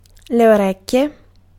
Ääntäminen
Ääntäminen Tuntematon aksentti: IPA: /o.ˈrek.kje/ Haettu sana löytyi näillä lähdekielillä: italia Käännös Ääninäyte 1. oor {n} Suku: m . Orecchie on sanan orecchio taipunut muoto.